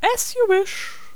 princess_ack4.wav